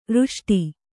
♪ řṣṭi